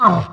WAV · 14 KB · 單聲道 (1ch)